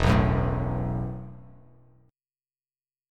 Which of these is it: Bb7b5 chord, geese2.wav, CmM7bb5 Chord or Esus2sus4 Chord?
Esus2sus4 Chord